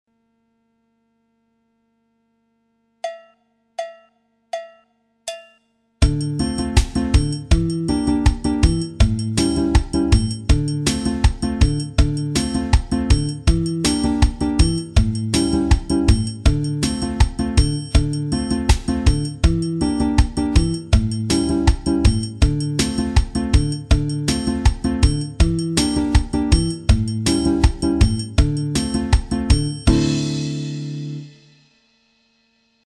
Variante 1 figure guitare xote 1,1 (midi) Téléchargez ou écoutez dans le player.
batida avec une syncope